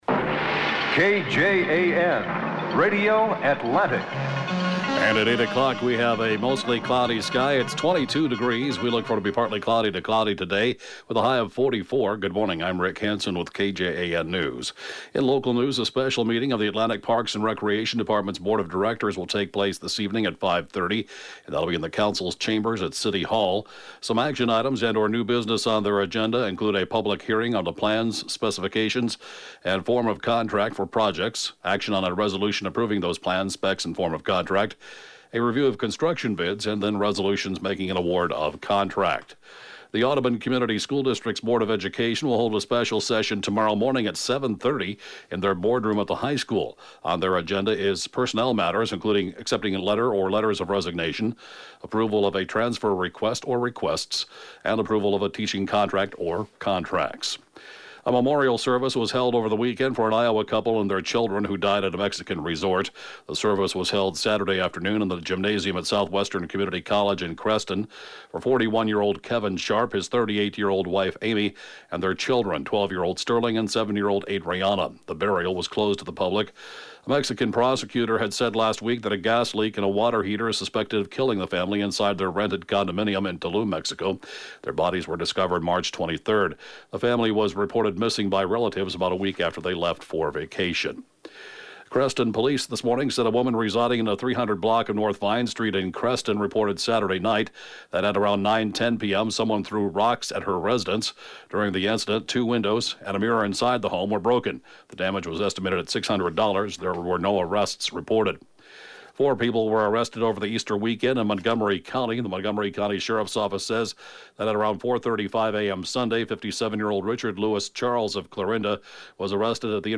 (Podcast) KJAN 8-a.m. News, 4/2/2018